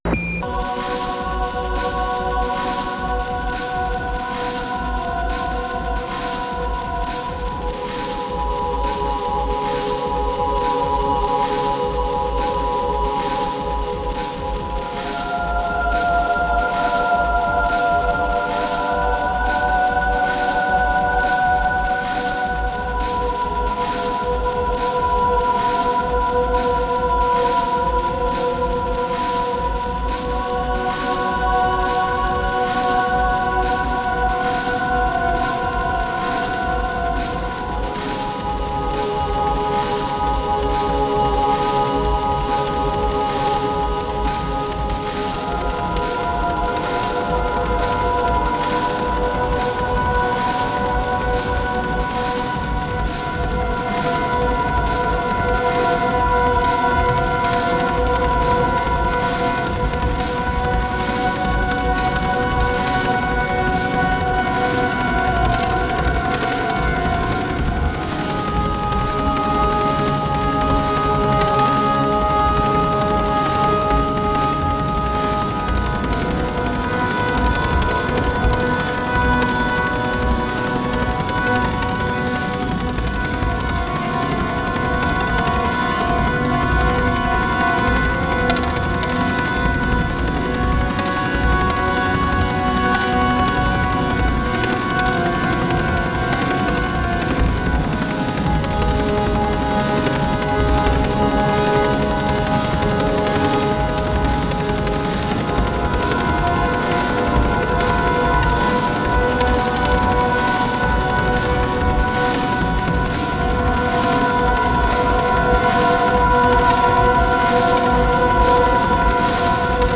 合唱、
音声モーフィング、
オルガン、
ハードコア・ビート、
不整動パンニング、
非実存ギターによるパーカッシブ・タッピング、